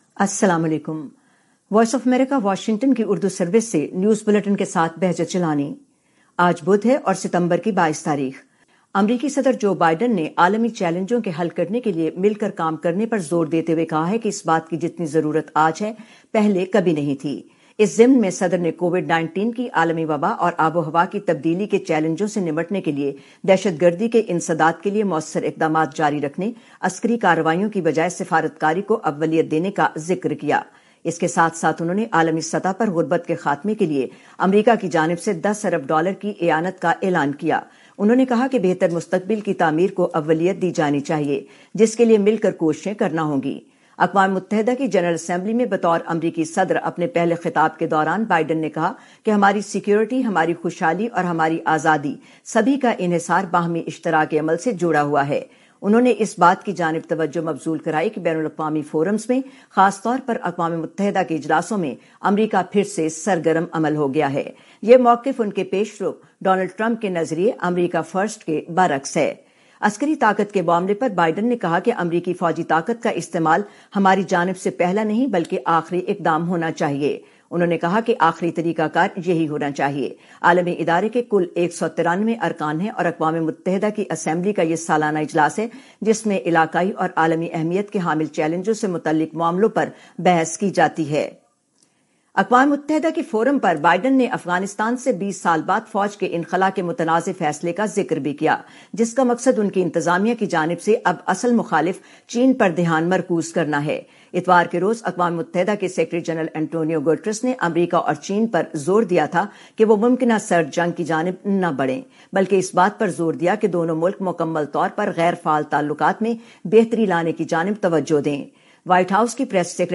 نیوز بلیٹن 2021-22-09